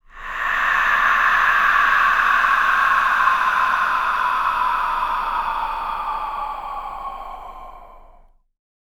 A-O SWEEP.wav